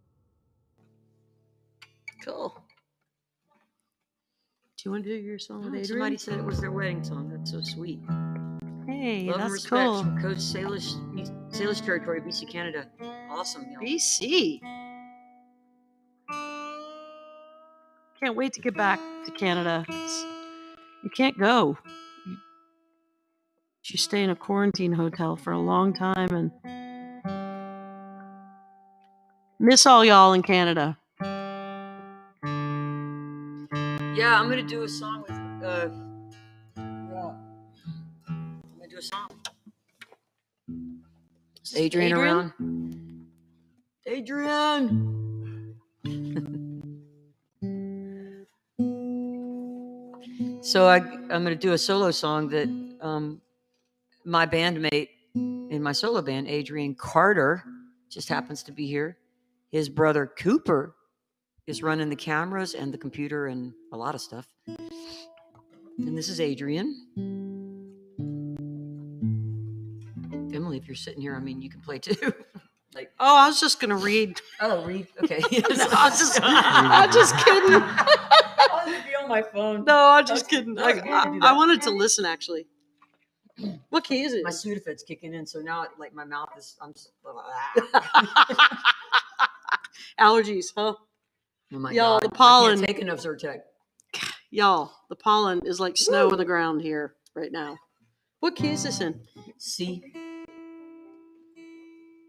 (captured from the youtube livestream)
05. talking with the crowd (1:42)